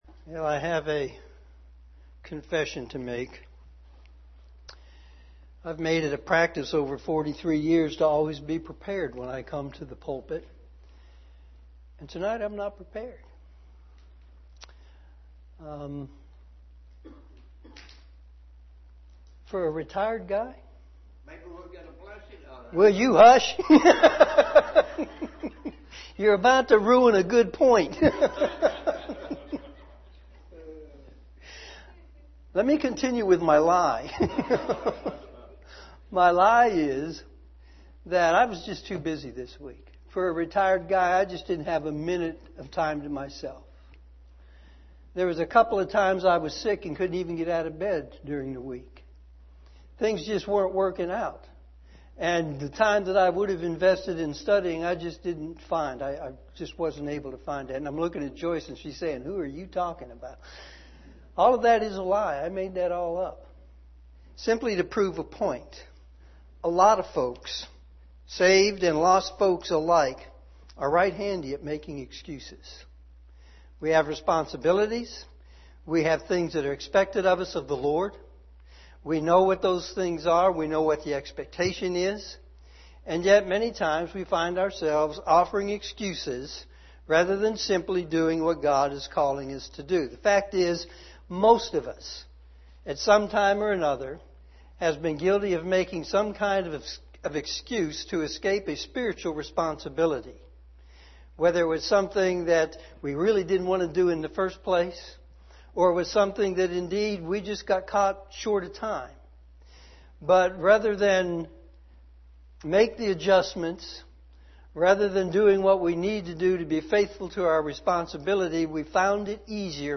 sermon7-28-19pm.mp3